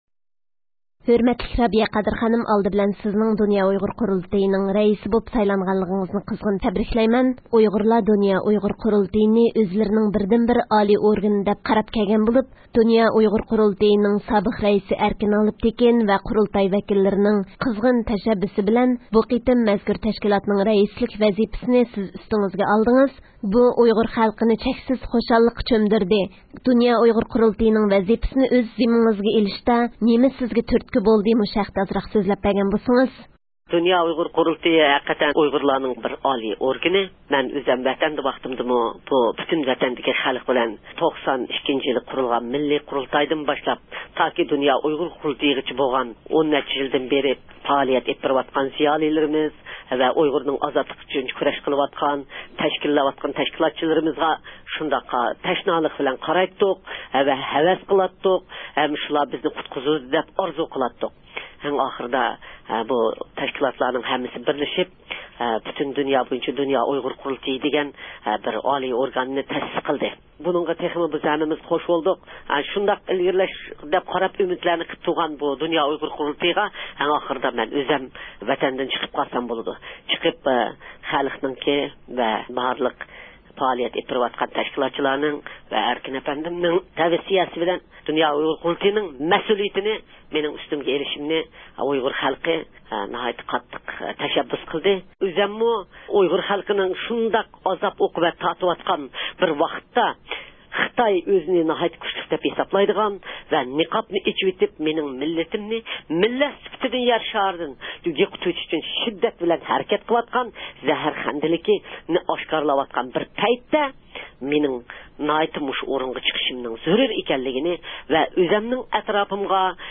د ئۇ ق نىڭ رەئىسى، ئۇيغۇرلارنىڭ مەنىۋىي ئانىسى رابىيە قادىر خانىم زىيارىتىمىزنى قوبۇل قىلىپ د ئۇ ق ھەققىدە توختالدى